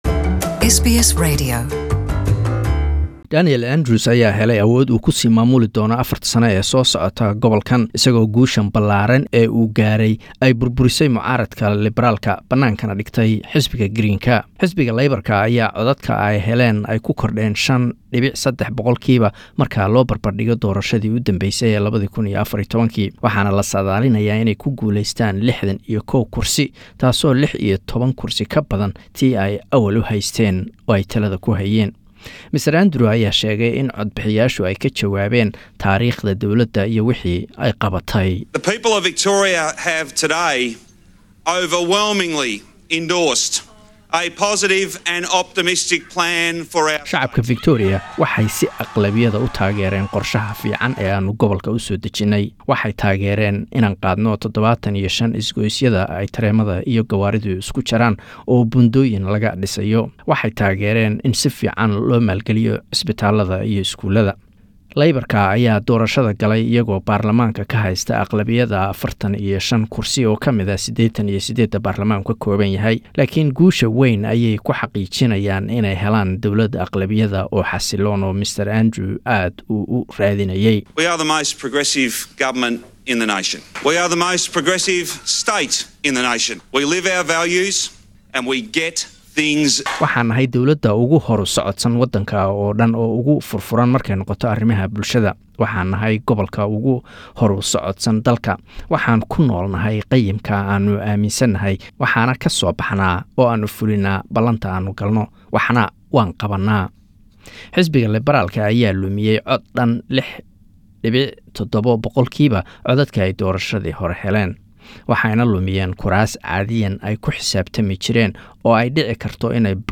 Warbixin: Guul wayn oo ugasoo hoyataya Laborka doorashada Victoria